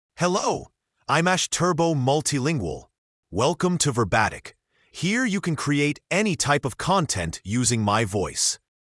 Ash Turbo MultilingualMale English AI voice
Ash Turbo Multilingual is a male AI voice for English (United States).
Voice sample
Listen to Ash Turbo Multilingual's male English voice.
Ash Turbo Multilingual delivers clear pronunciation with authentic United States English intonation, making your content sound professionally produced.